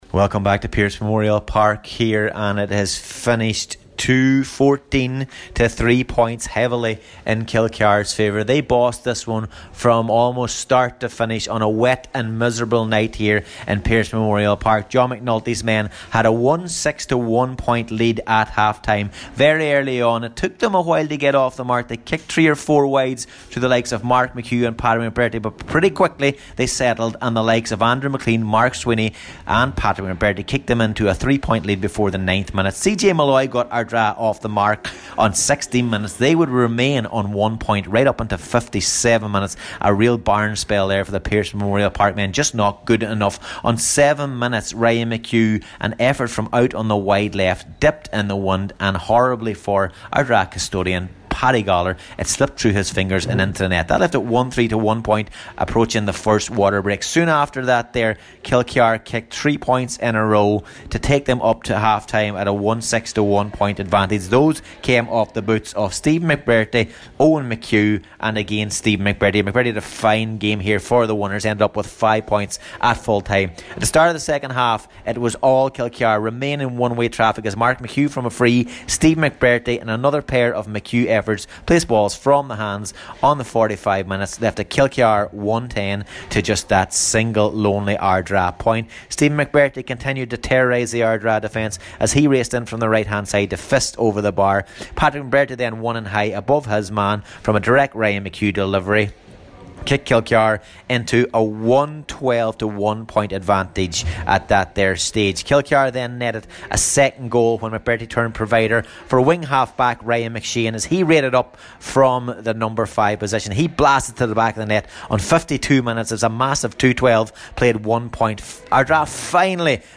reports from Pearse Memorial Park in Kentucky: